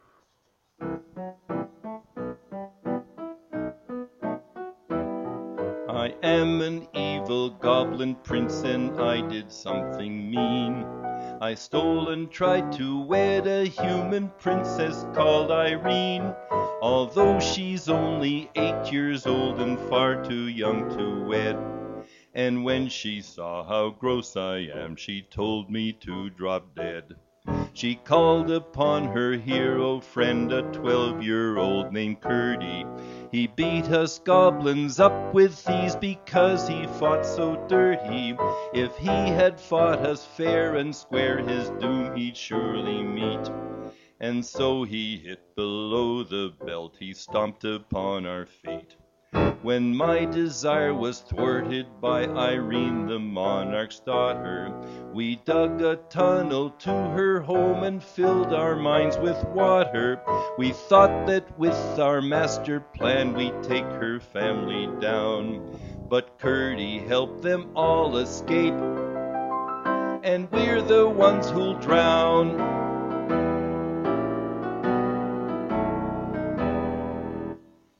Songs sung by humans